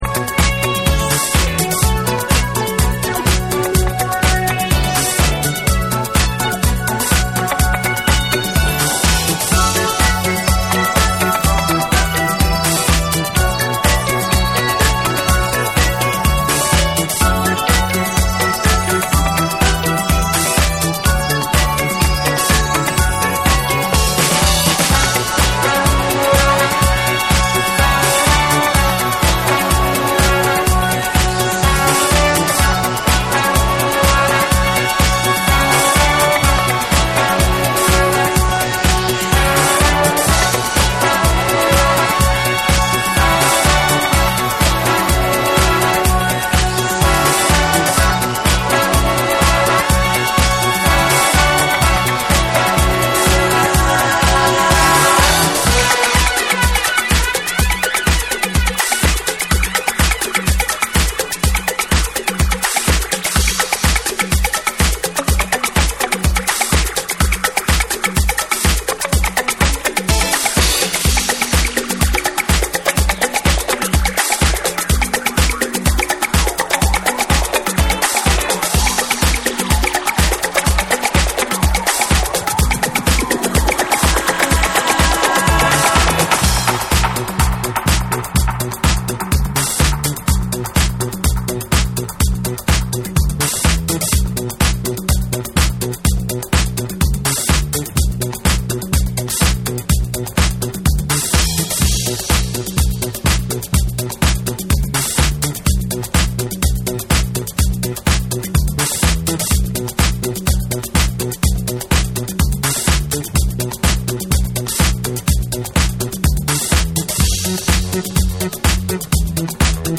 TECHNO & HOUSE / DISCO DUB